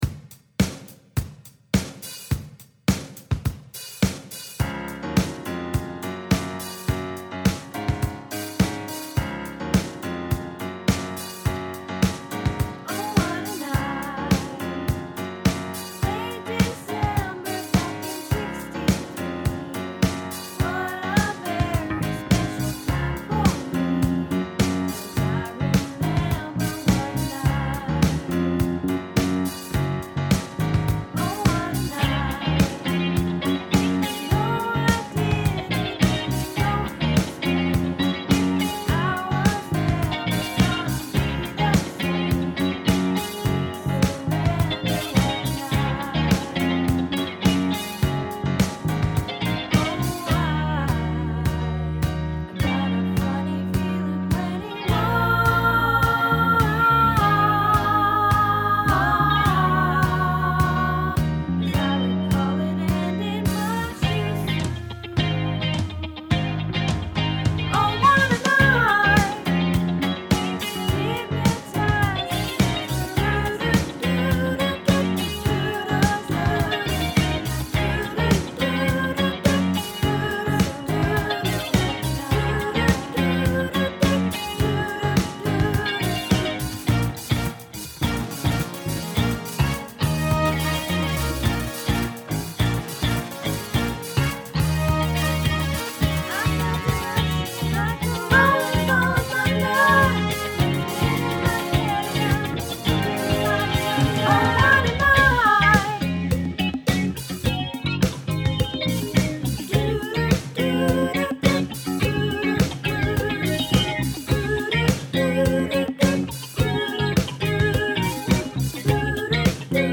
Oh What A Night - Soprano